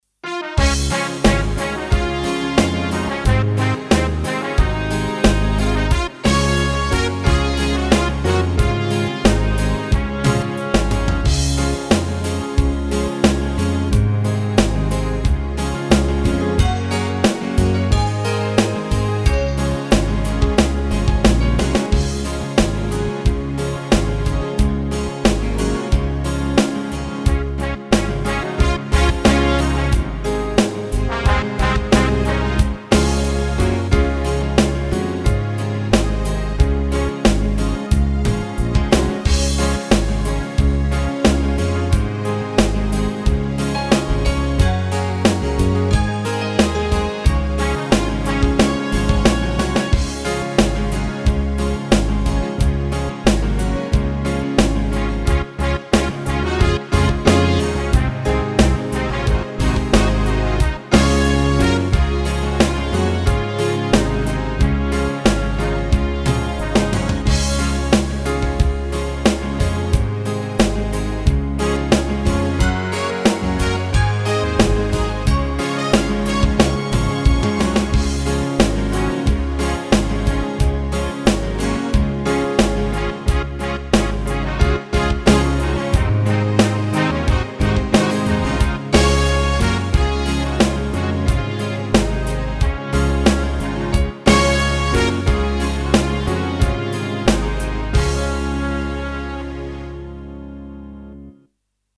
KRISMAS ZING PROGRAMME
Hla sak hona: KHB: 79 “Khawvel lawm nan a lo kal a” (Thilpek lakkhawmna)